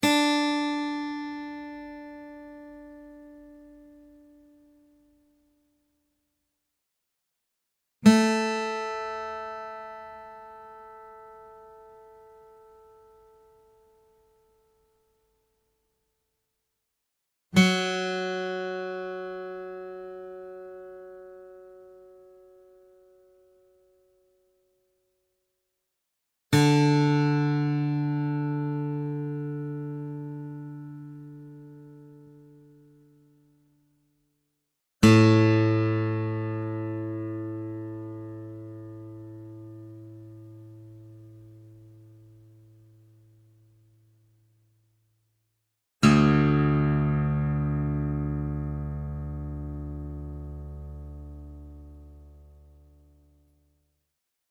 Real acoustic guitar sounds in Open D Tuning
Guitar Tuning Sounds